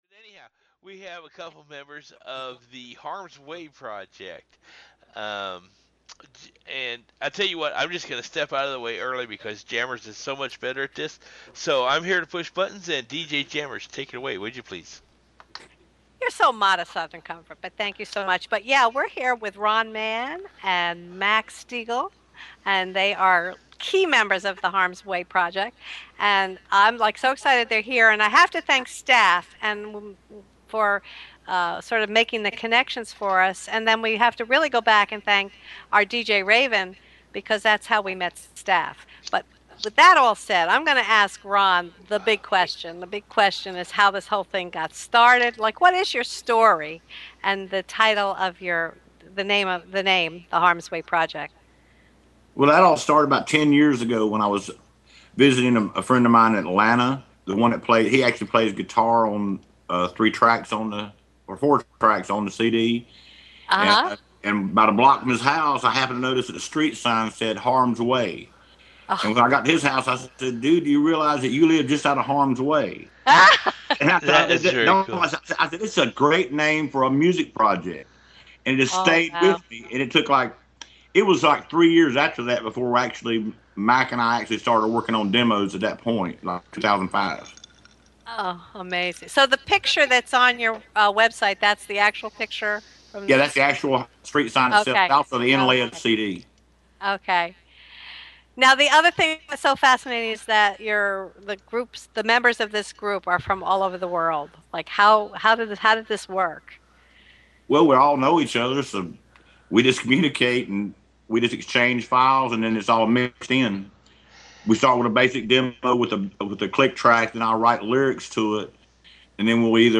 Harms_Way_Interview.mp3